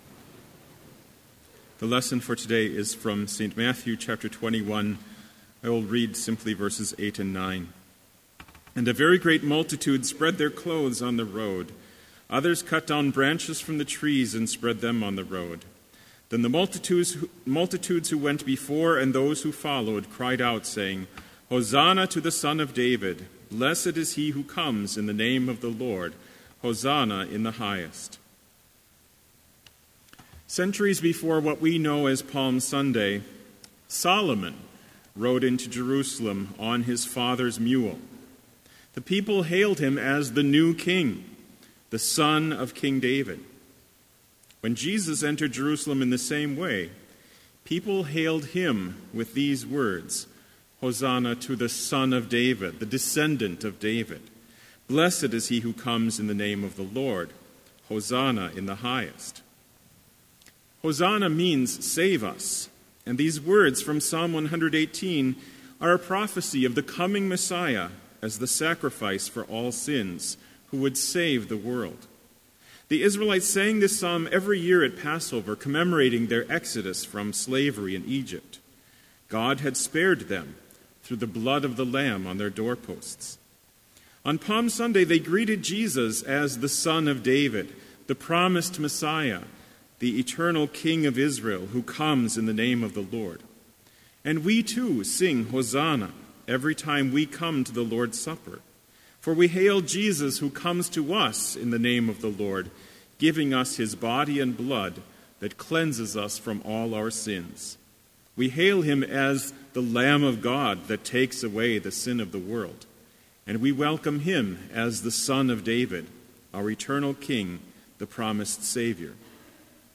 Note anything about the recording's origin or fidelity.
This Chapel Service was held in Trinity Chapel at Bethany Lutheran College on Tuesday, November 29, 2016, at 10 a.m. Page and hymn numbers are from the Evangelical Lutheran Hymnary.